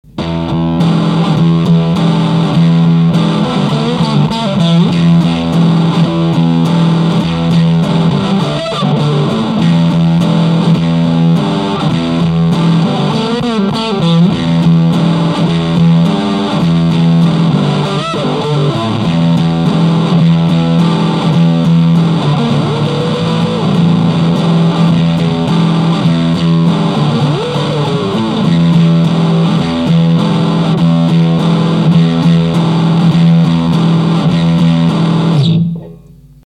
やはり、６弦５弦の音が自分には潰れ過ぎていると